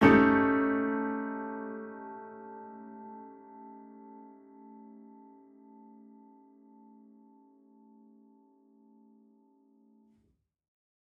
Index of /musicradar/gangster-sting-samples/Chord Hits/Piano
GS_PiChrd-A7b5.wav